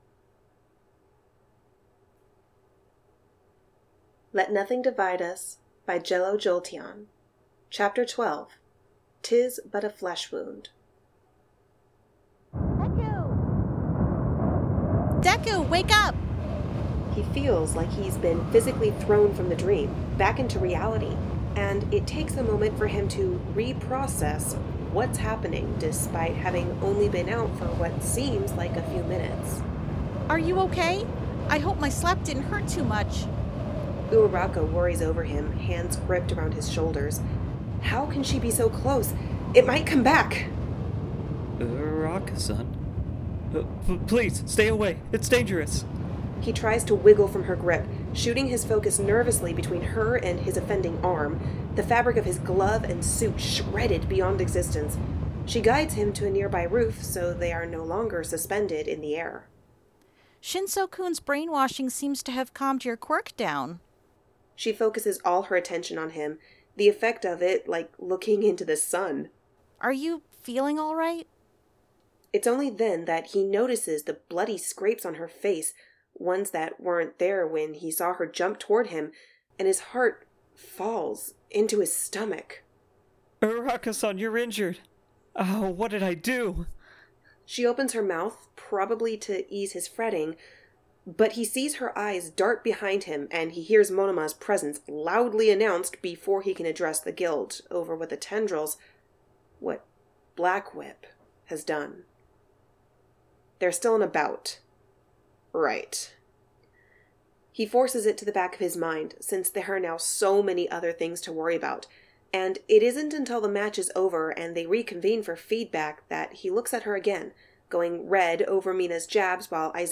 This podfic uses 4 licensed assets.